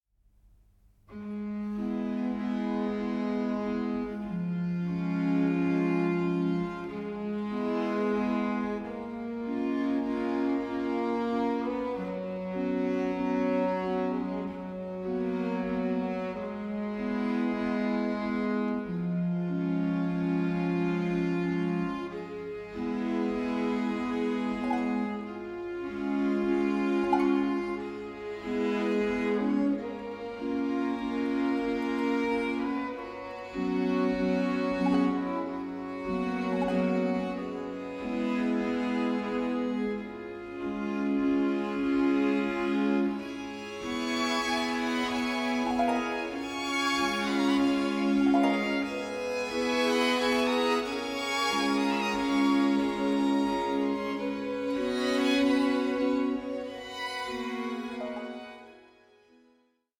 BAROQUE MEETS THE ORIENT: MUSIC AS INTERCULTURAL DIALOGUE